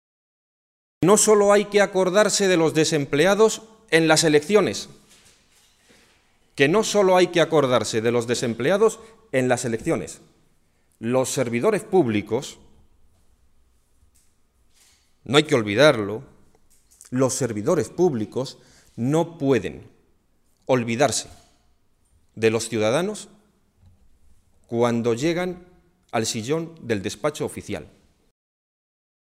Alcaldes socialistas de las cinco provincias de Castilla-La Mancha comparecieron en rueda de prensa para informar sobre diferentes iniciativas políticas que se llevarán a cabo en los ayuntamientos de nuestra comunidad autónoma
Ha ejercido de portavoz Santiago García-Aranda, alcalde del municipio toledano de Villacañas.